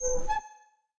Sfx Monkeybars Squeaky Sound Effect
sfx-monkeybars-squeaky.mp3